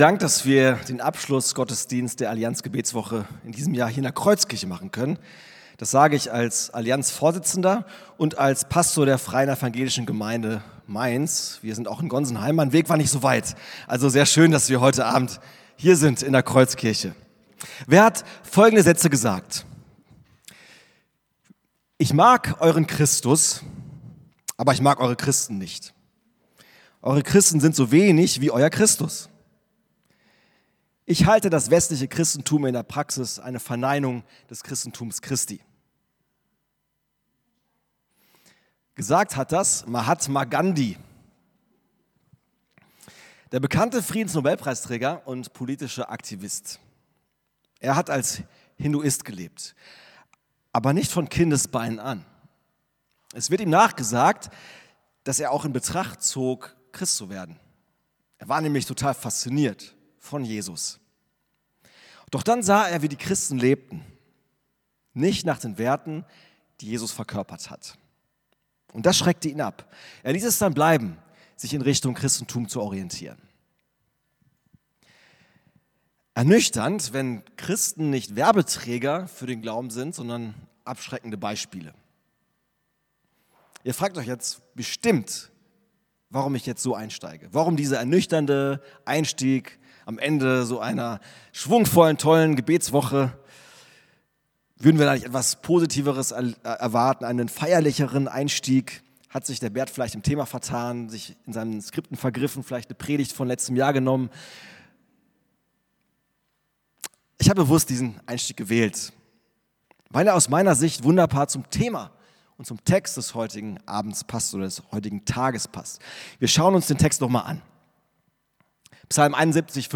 Predigt vom 18.01.2026